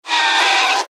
Gemafreie Sounds: Spielzeug
mf_SE-6342-squeek.mp3